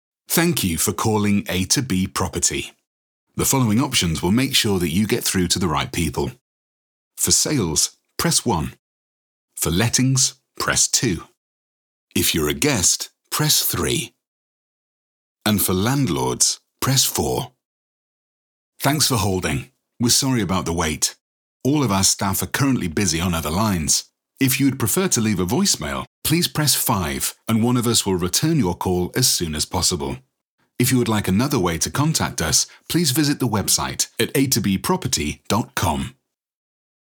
From Northern accent to well spoken RP and everything in between
IVR/On Hold
VOICE-REEL-IVR-ON-HOLD-17.9.25.mp3